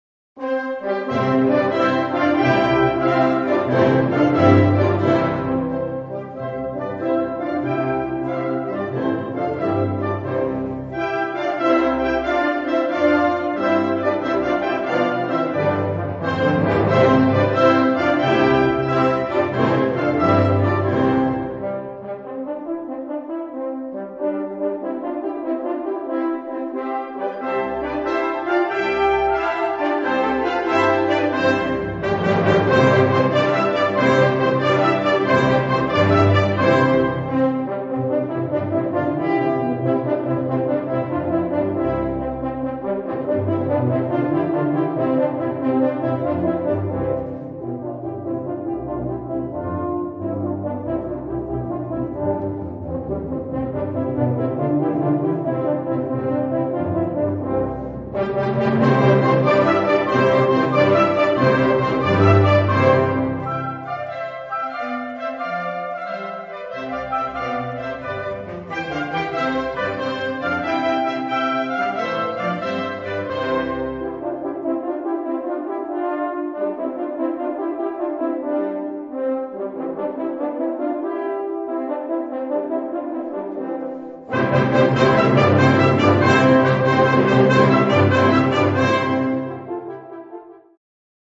for Horns and Wind Band
Besetzung: Blasorchester